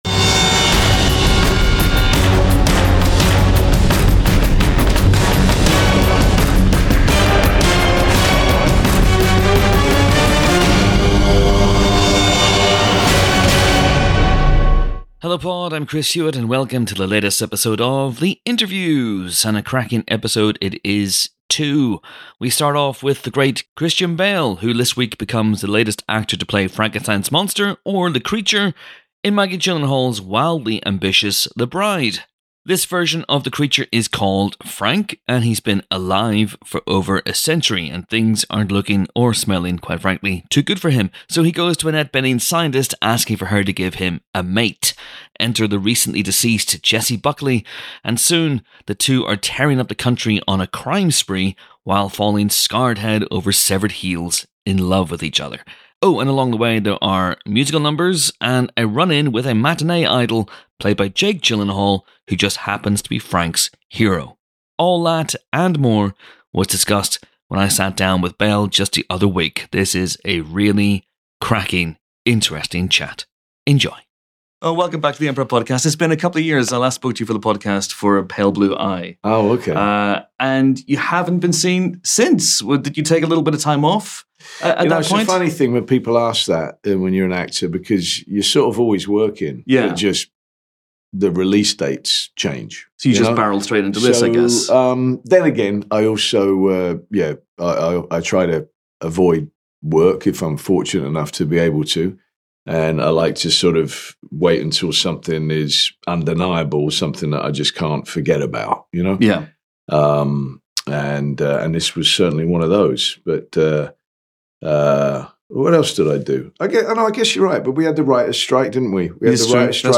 The Interviews (ft. Christian Bale, Piper Curda & Bobby Moynihan, Edgar Wright)